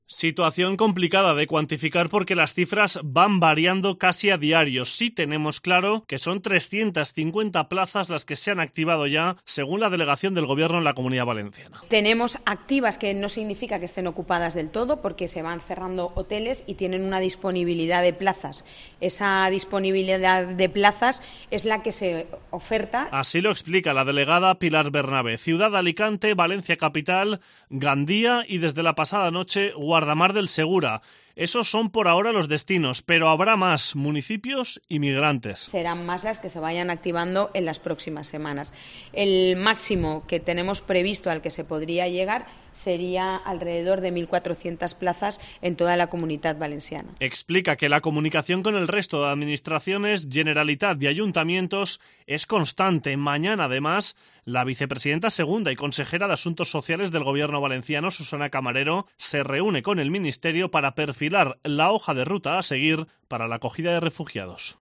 Pilar Bernabé, delegada del Gobierno, sobre la llegada de migrantes derivados de Canarias
Bernabé se ha pronunciado así, en declaraciones a los medios de comunicación, tras mantener una reunión con representantes de distintas ONGs que atienden a personas migrantes procedentes de las Islas Canarias.